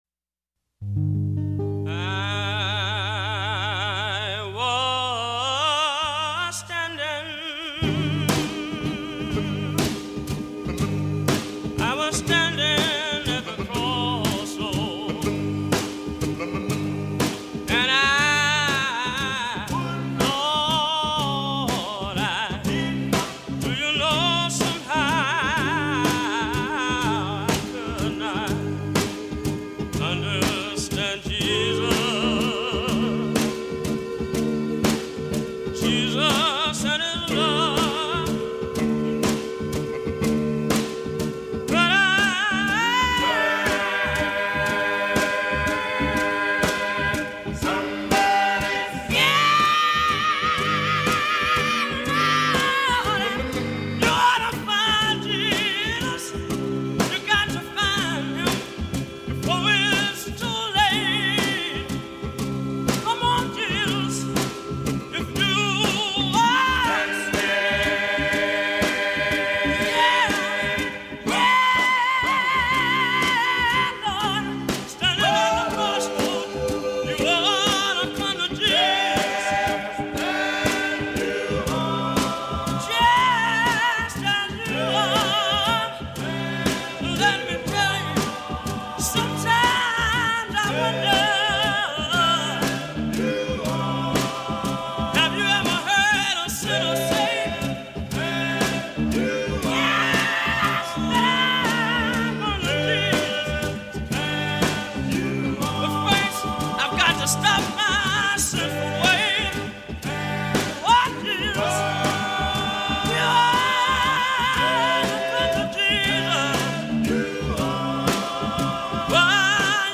field recording collection